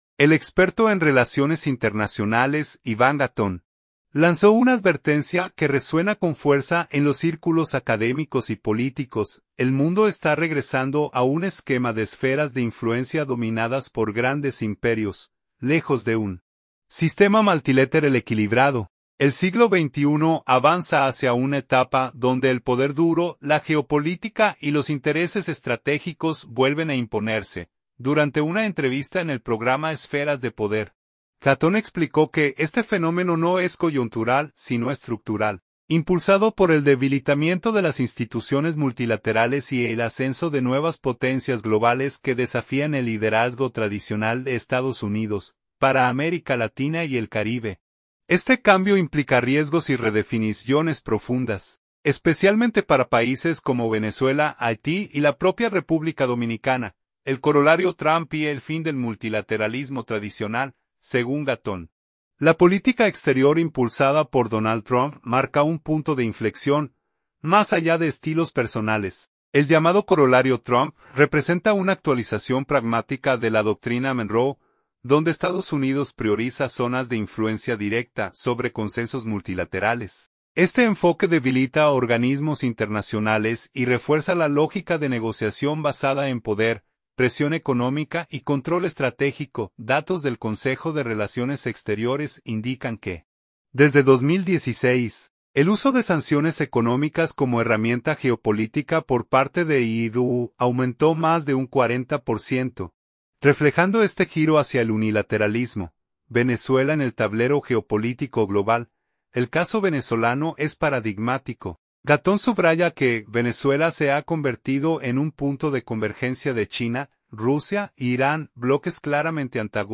Durante una entrevista en el programa Esferas de Poder